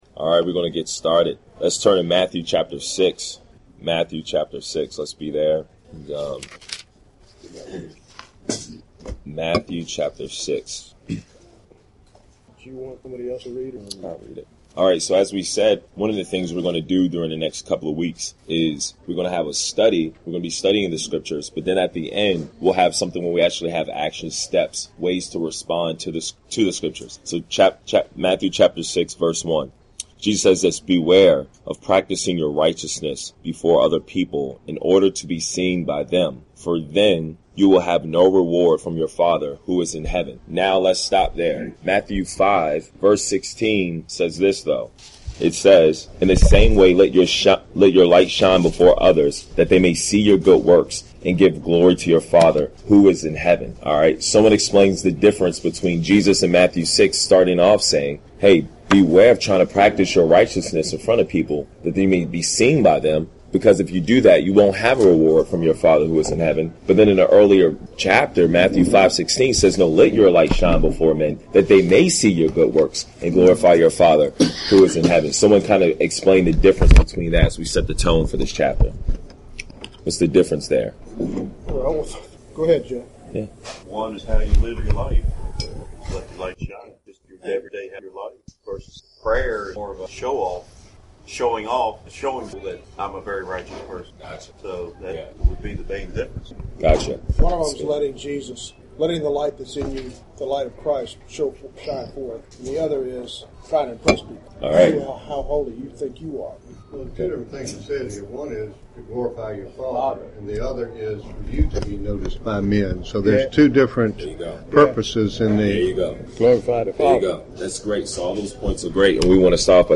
Here is the recording of the teaching and the discussion. Hope you are challenged by it like we were.